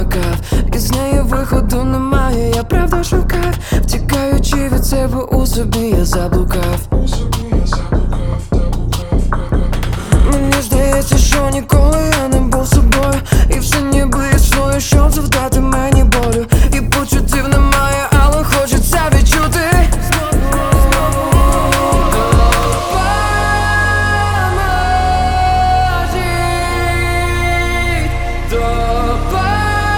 Жанр: Электроника / Украинские